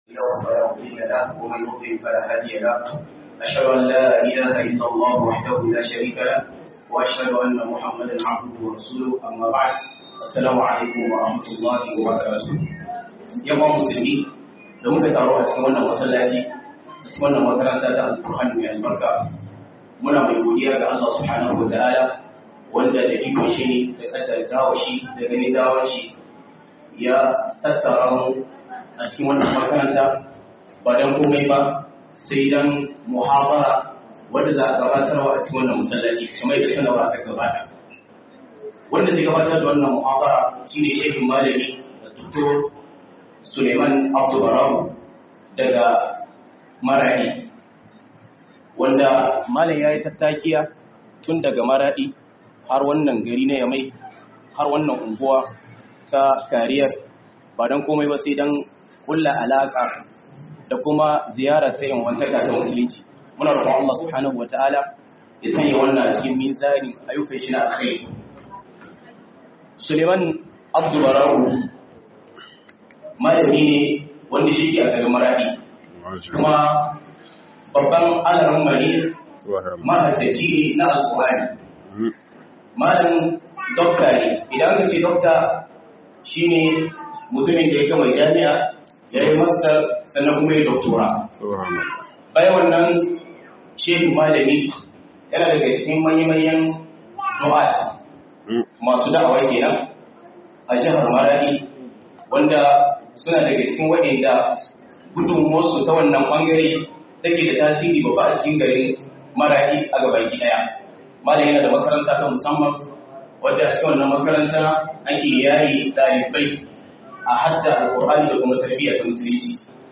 MUHADARA A NIAMEY 03